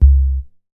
MoogQUartz 002.WAV